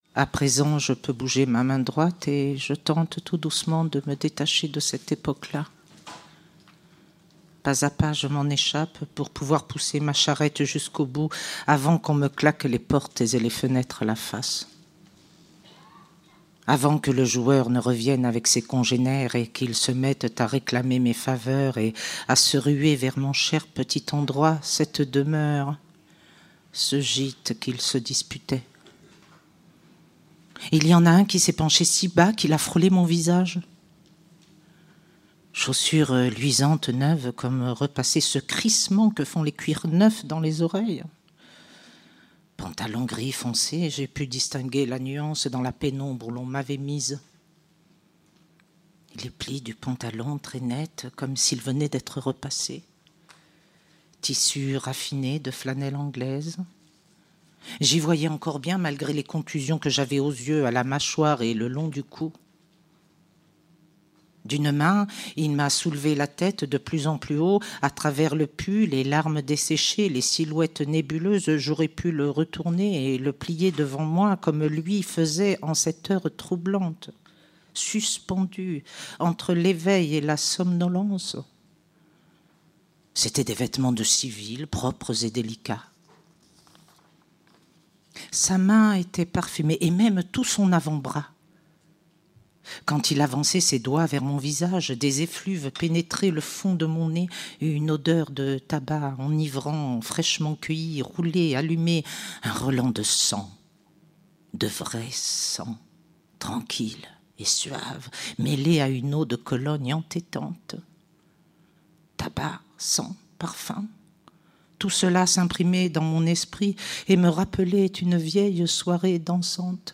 ساعة مع عالية ممدوح. حوار باللغتين الفرنسية والعربية